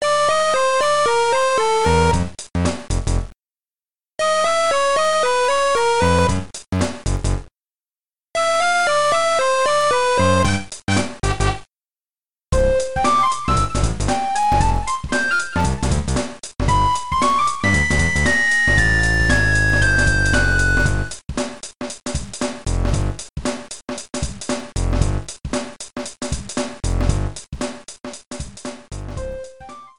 Trimmed and fade out